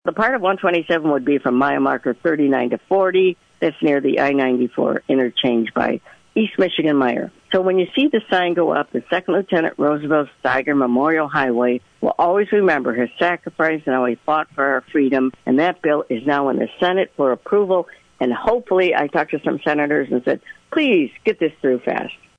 This full interview is available to listen to and download on this website, and State Representative Kathy Schmaltz regularly joins A.M. Jackson on Friday mornings.